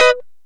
Lng Gtr Chik Min 04-G#2.wav